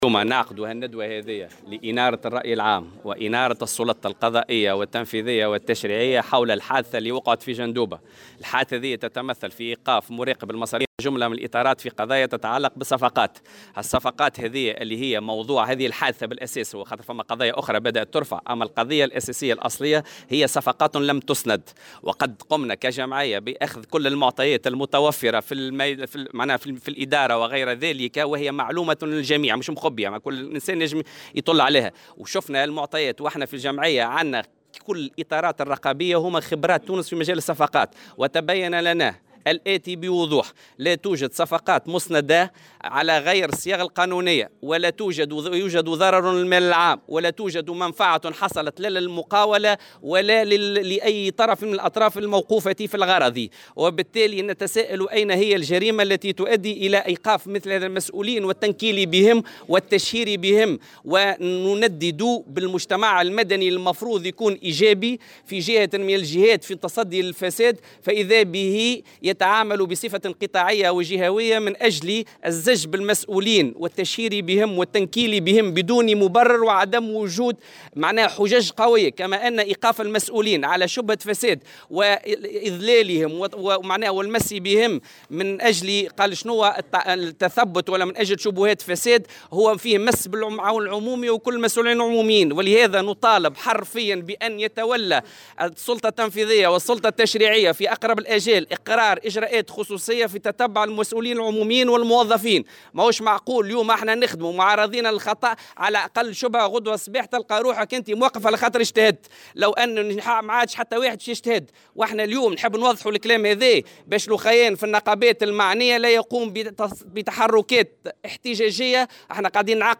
وأضاف في تصريح لمراسل "الجوهرة أف أم" على هامش ندوة صحفية اليوم الثلاثاء، أنه تبين عدم وجود أي ضرر بالمال العام ومنفعة حصلت لأي طرف من الأطراف الموقوفة في هذه القضية، وأن الهياكل الرقابية أثبتت سلامة التصرف في الصفقات.